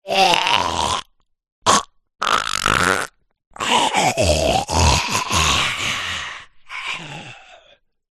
Звуки зомби
Здесь собраны жуткие стоны, агрессивное рычание, скрежет зубов и другие пугающие эффекты, которые помогут создать напряженную обстановку в вашем проекте.
Зомби захлебнулся